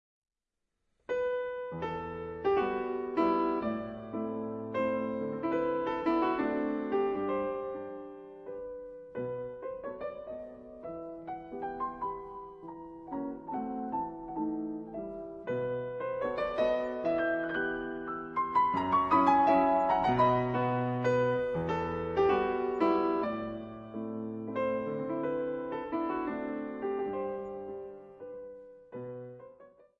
fortepian / piano